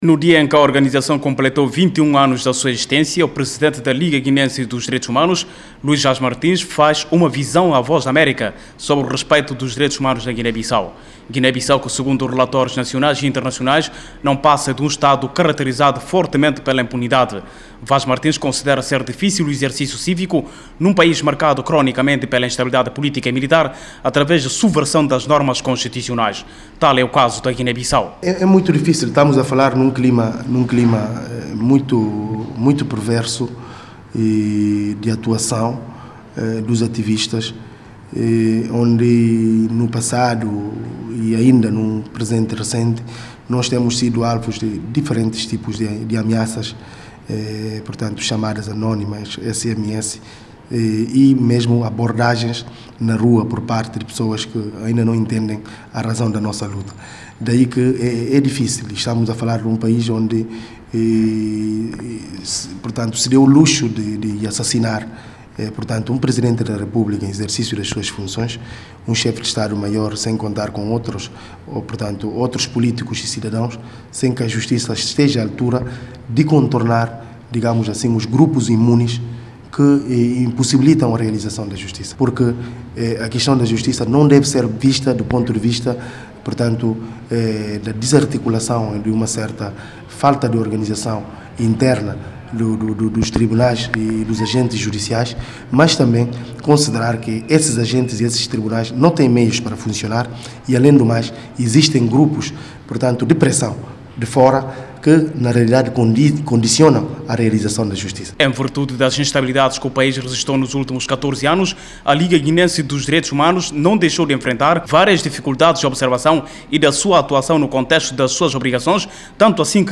entrevista ao presidente da liga